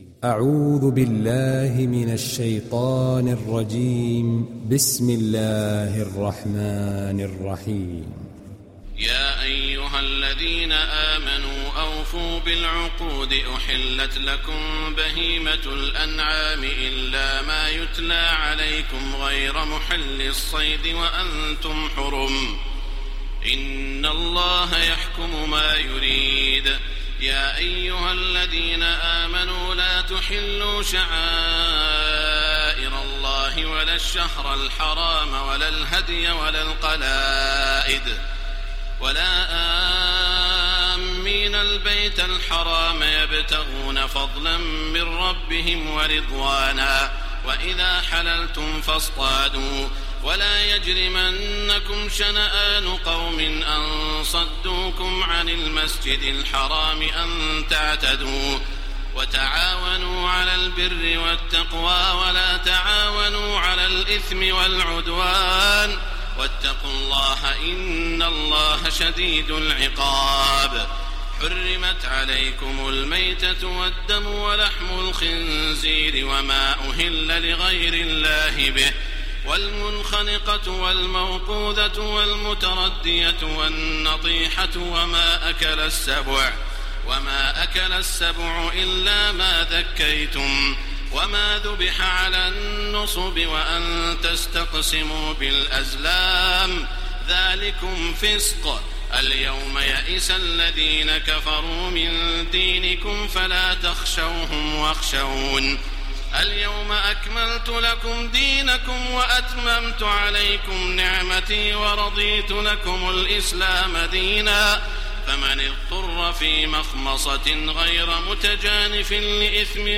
Maide Suresi İndir mp3 Taraweeh Makkah 1430 Riwayat Hafs an Asim, Kurani indirin ve mp3 tam doğrudan bağlantılar dinle
İndir Maide Suresi Taraweeh Makkah 1430